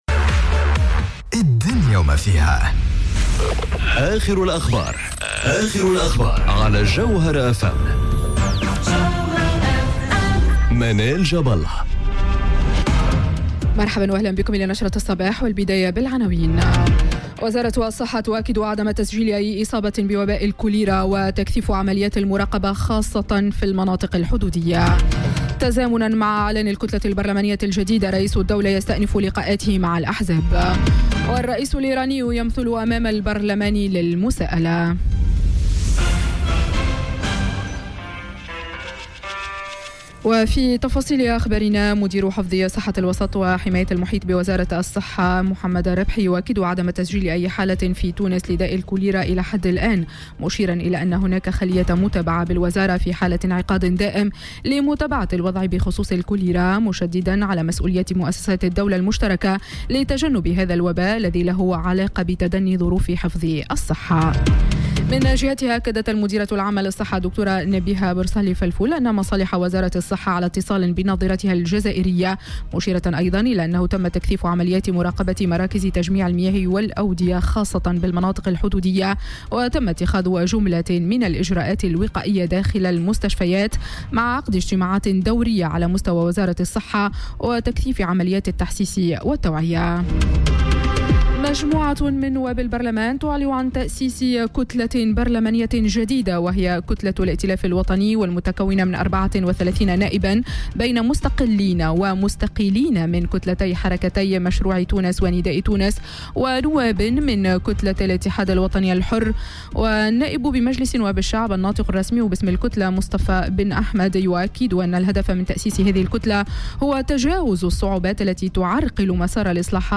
نشرة أخبار السابعة صباحا ليوم الثلاثاء 28 أوت 2018